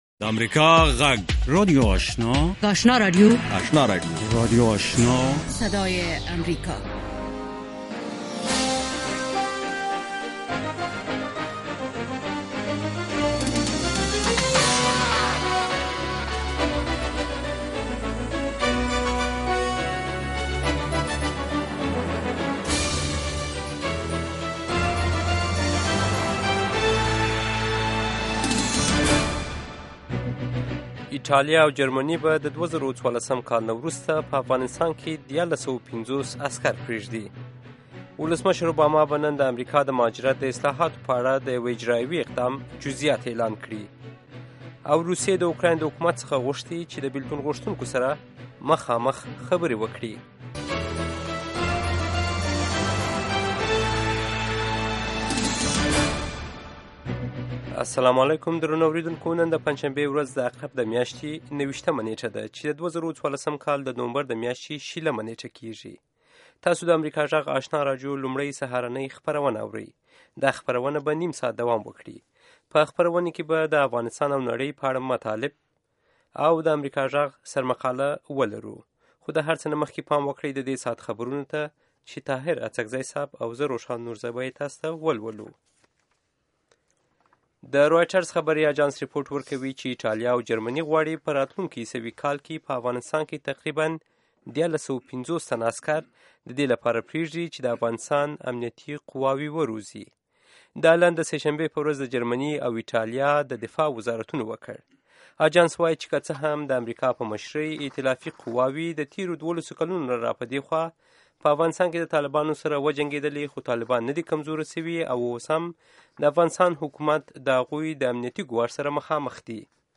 لومړنۍ سهارنۍ خبري خپرونه